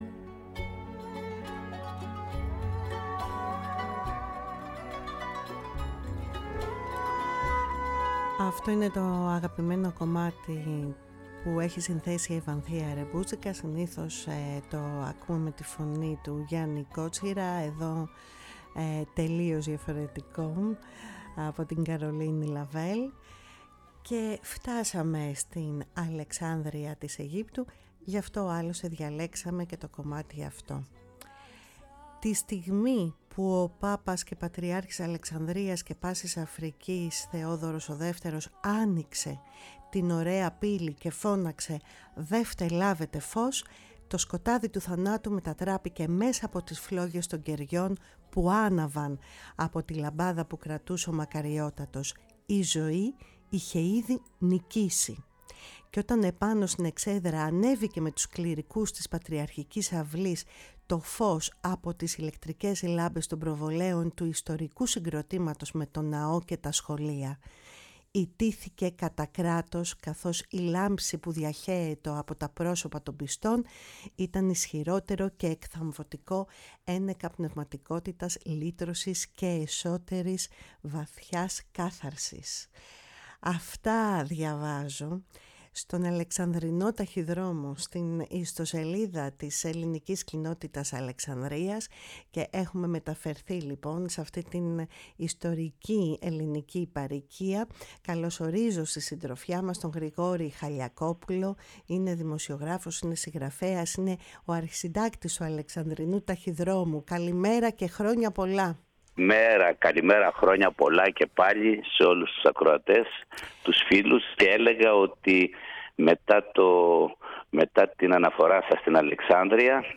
Το Πάσχα στην Αλεξάνδρεια – Μια συνομιλία